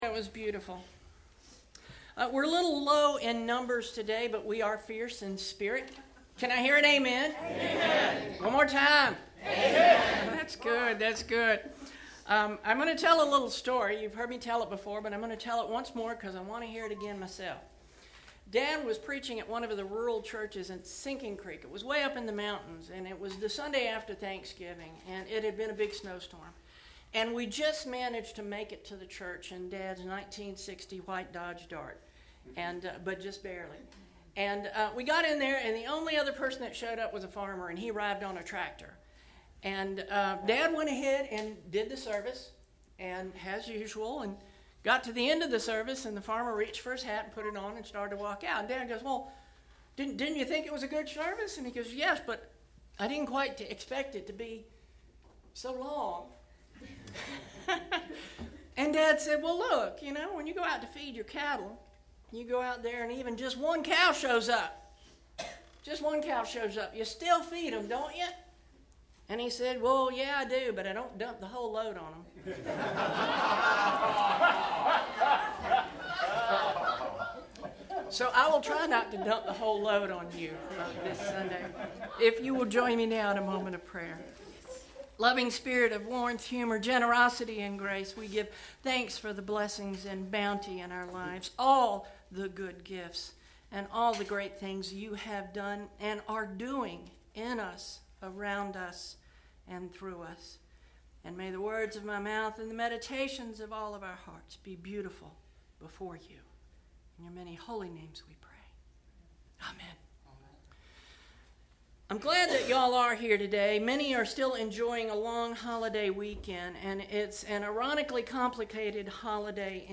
11/26 Sermon Posted (Click to listen) How We Give, How We Live – “Give Thanks!”